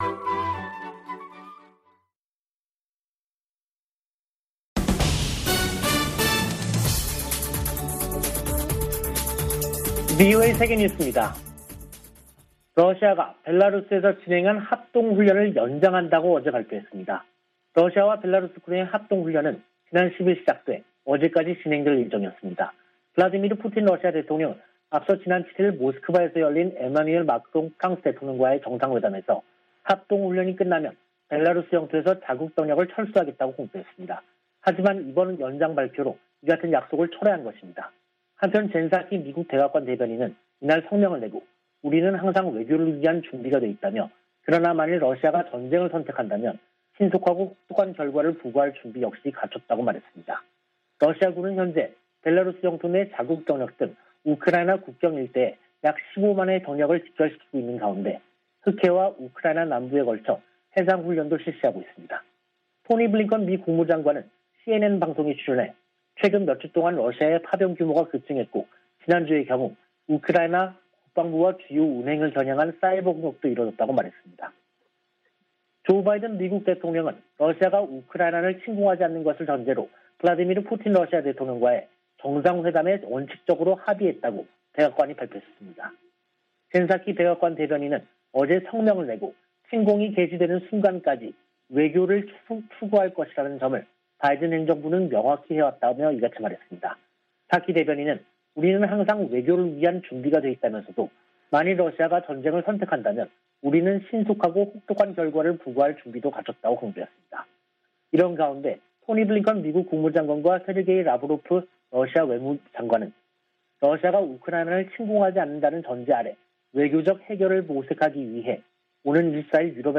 VOA 한국어 간판 뉴스 프로그램 '뉴스 투데이', 2022년 2월 21일 3부 방송입니다. 존 볼튼 전 백악관 국가안보보좌관은 북한 정권 교체 가능성과 핵 프로그램에 대한 무력 사용이 배제돼선 안 된다고 주장했습니다. 북한 비핵화 문제는 미-북 간 최고위급 논의가 필요하다고 마이크 폼페오 전 미 국무장관이 말했습니다. 한국의 북한 출신 국회의원들이 유엔 북한인권특별보고관에게 북한 반인도 범죄자들에 고소·고발 지원을 요청했습니다.